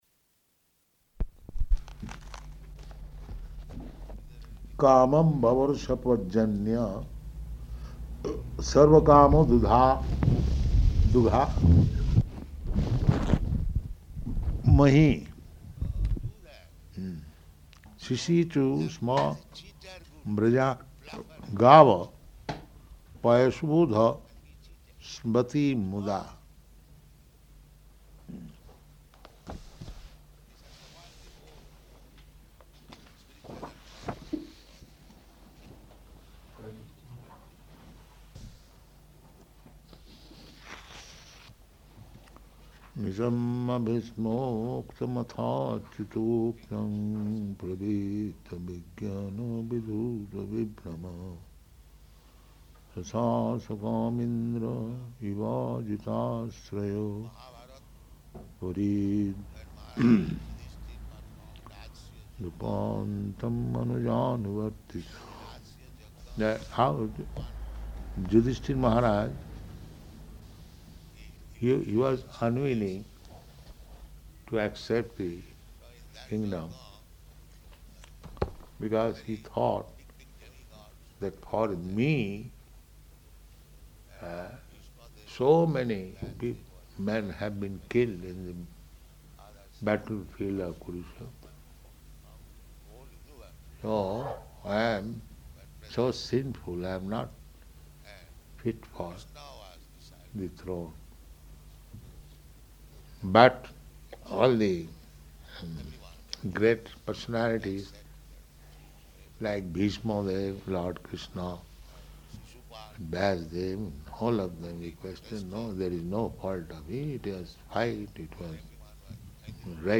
Type: Srimad-Bhagavatam
Location: Tehran